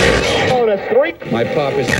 120BPMRAD9-R.wav